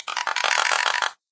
robot_rattle.ogg